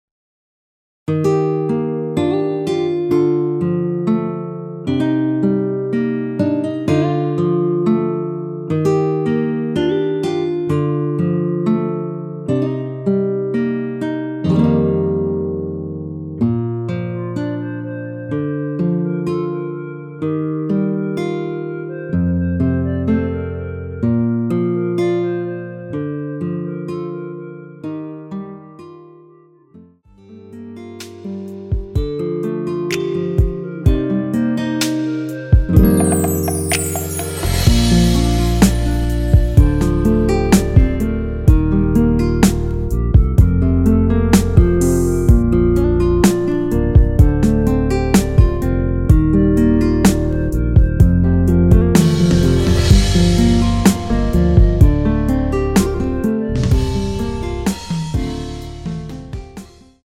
원키에서(-2)내린 멜로디 포함된 MR입니다.
Bb
앞부분30초, 뒷부분30초씩 편집해서 올려 드리고 있습니다.
(멜로디 MR)은 가이드 멜로디가 포함된 MR 입니다.